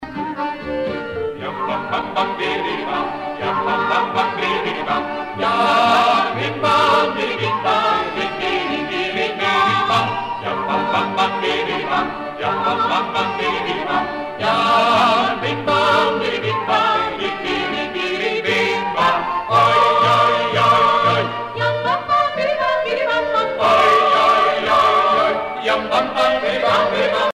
Prières et chants religieux